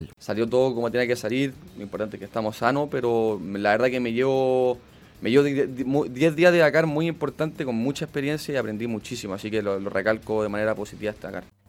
A tres semanas del hecho, Barbosa entregó nuevos detalles en entrevista con Radio Bío Bío en Concepción.